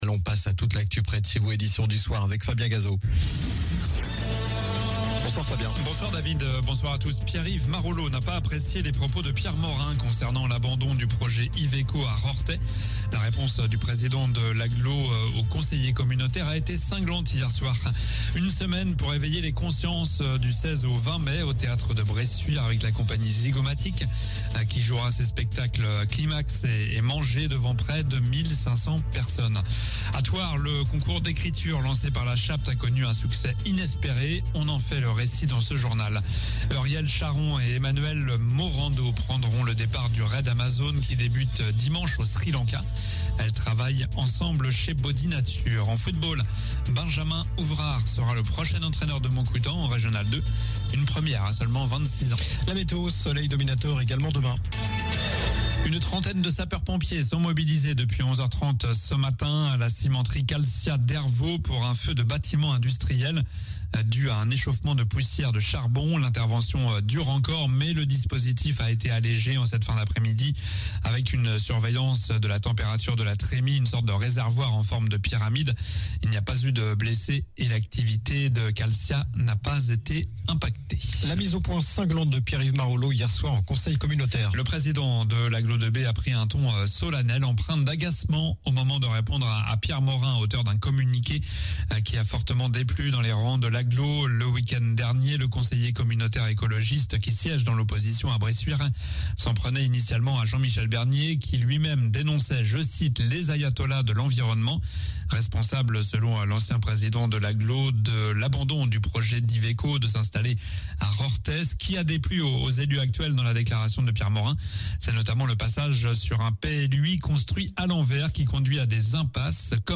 Journal du mercredi 23 mars (soir)